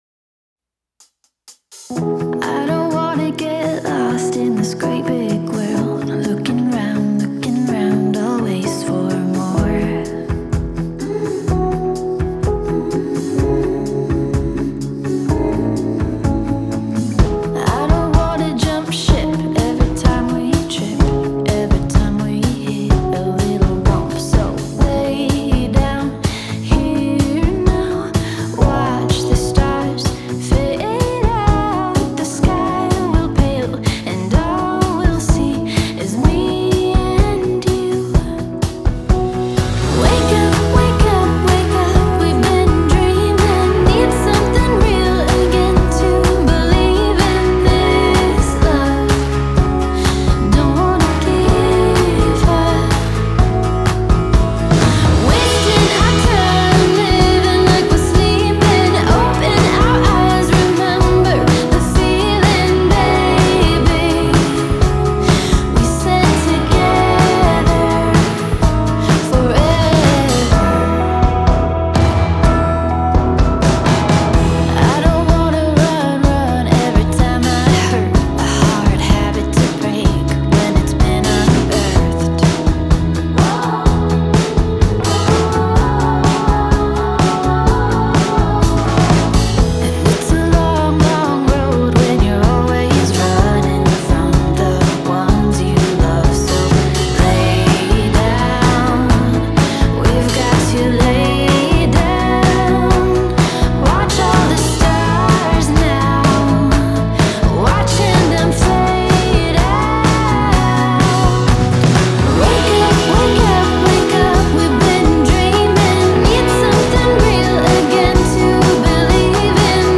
★★new 女性ボーカルのかわいい楽曲です。
女性Vo 03：47